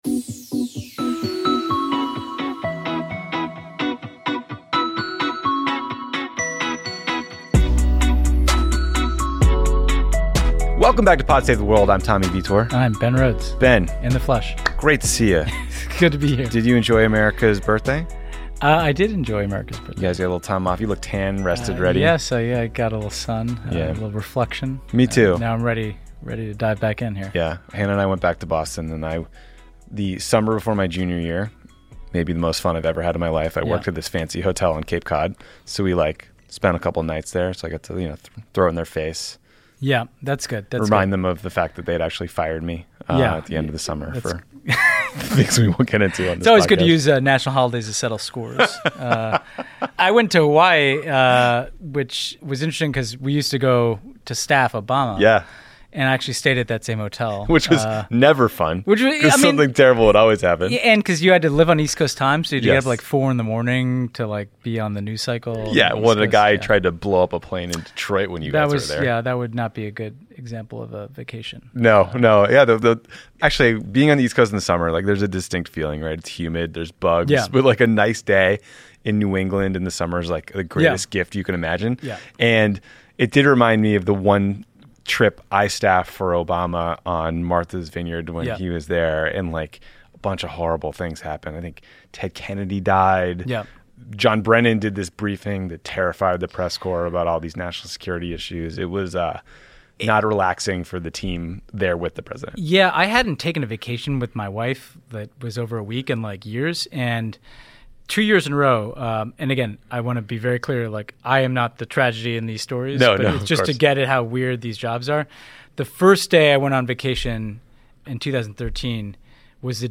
Introducing America’s chief diplomat, Ivanka Trump, and a 2020 foreign policy update. Then Turkish journalist Ece Temelkuran joins to discuss Turkey’s slow descent into authoritarianism and the parallels around the world.